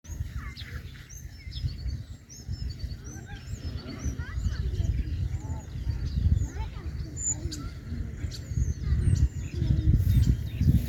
скворец, Sturnus vulgaris
Ziņotāja saglabāts vietas nosaukumsSkolas parks Ērberģē
СтатусПоёт